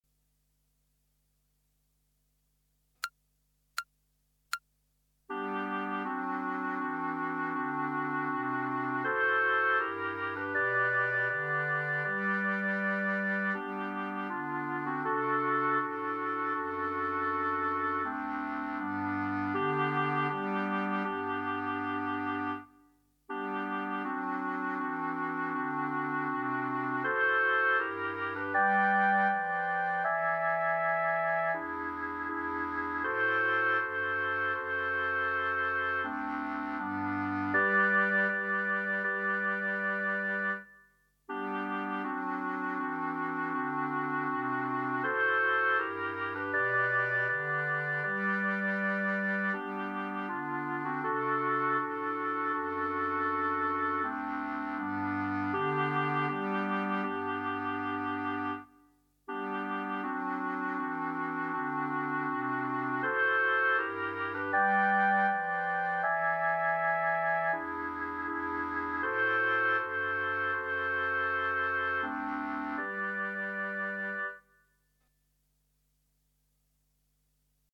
Clarinet Ensemble